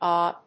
As an interjection the word is pronounced basically the same way as the interjection ah but the double a stresses prolongation.